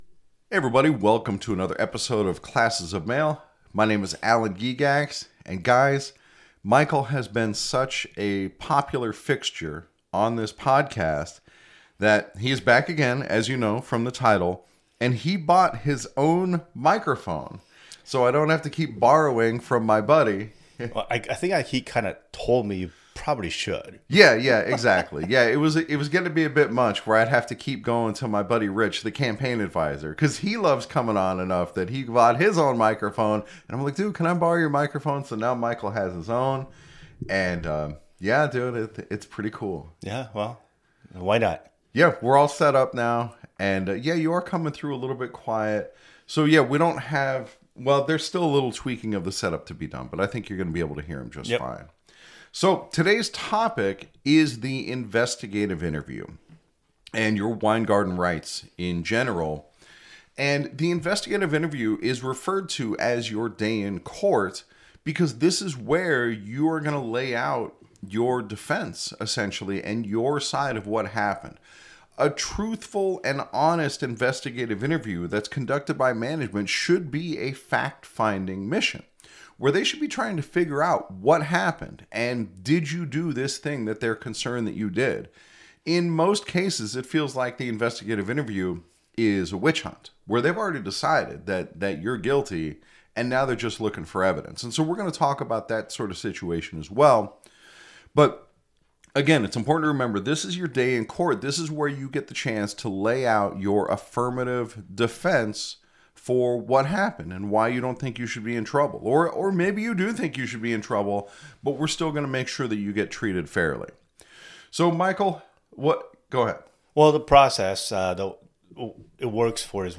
He went with the Rode PodMic. It sounds pretty good. Anyway, this episode is about the importance of the investigative interview.